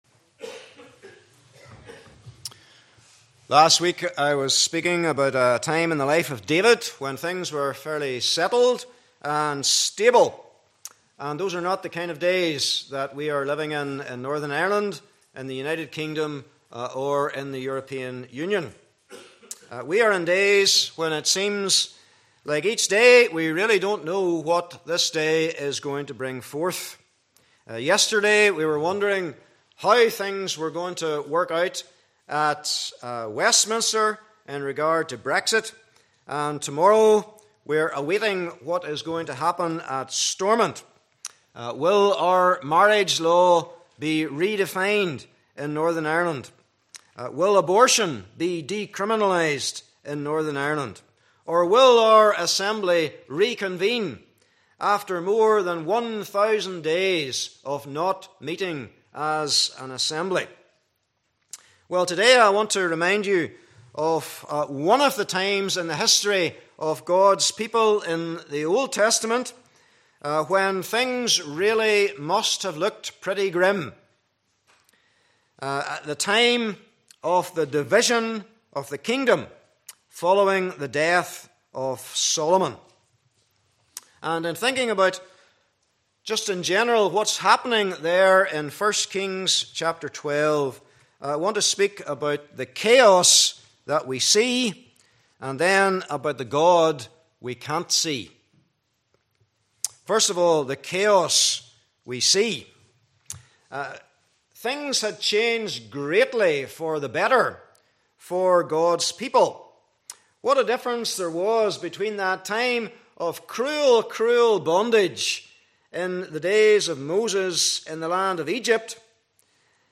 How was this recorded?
Passage: 1 Kings 12:1-33 Service Type: Morning Service